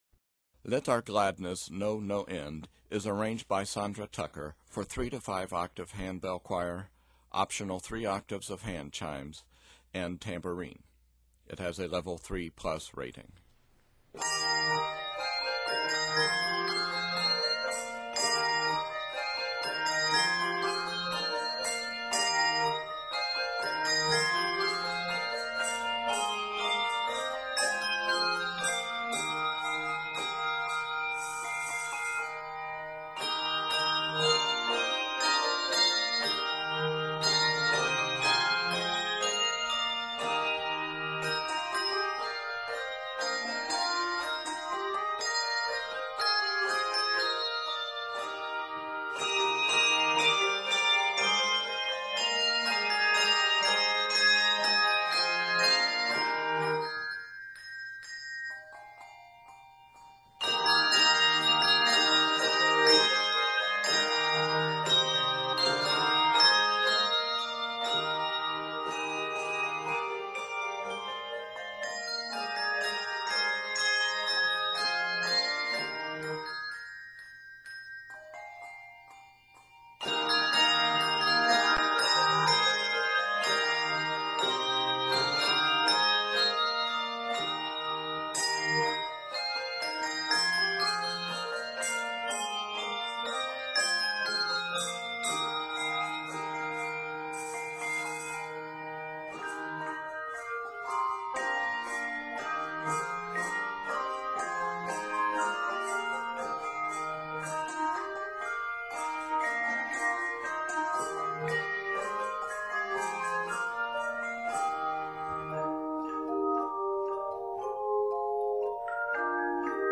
is set in Eb Major